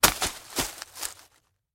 Звуки граблей
На этой странице собраны реалистичные звуки граблей – от мягкого шелеста листьев до характерного скрежета по земле.
Звук падения граблей на сухую траву